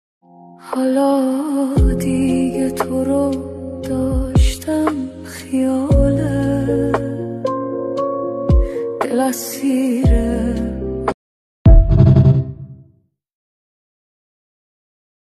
🎤 خواننده : صدای زن